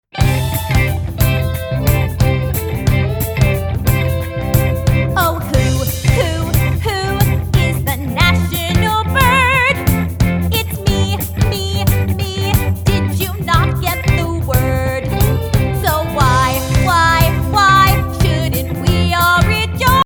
Downloadable Musical Play with Album Sheet Music.